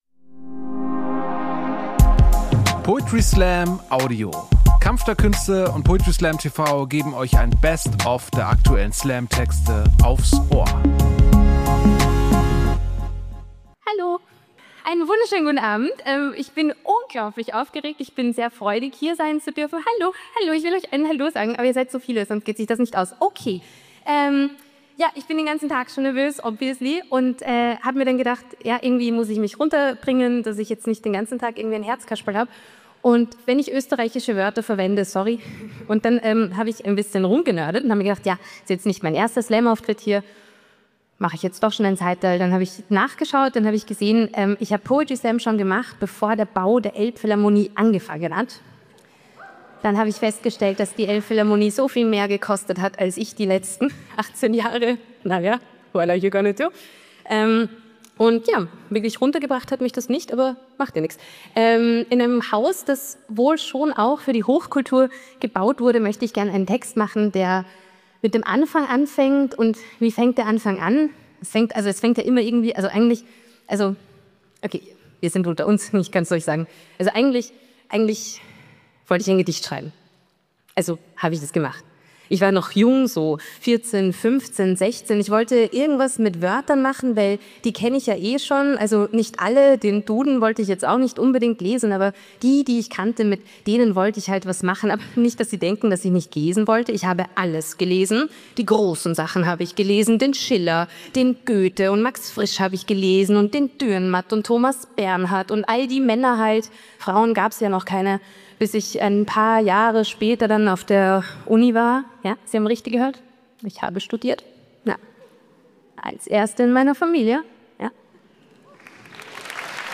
Stage: Elbphilharmonie, Hamburg